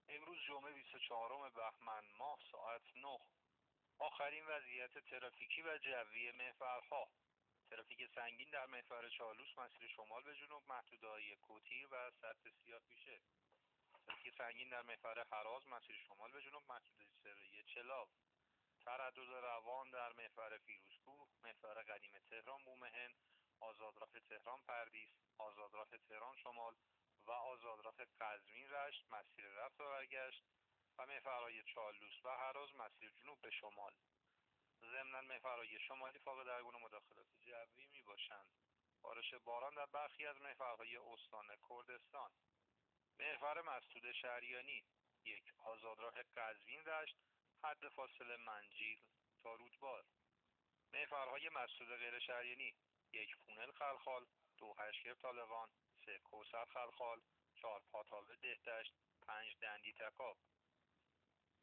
گزارش آخرین وضعیت ترافیکی جاده‌های کشور را از رادیو اینترنتی پایگاه خبری وزارت راه و شهرسازی بشنوید.
گزارش رادیو اینترنتی از آخرین وضعیت ترافیکی جاده‌ها ساعت ۹ بیست و چهارم بهمن؛